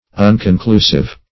Unconclusive \Un`con*clu"sive\, a.
unconclusive.mp3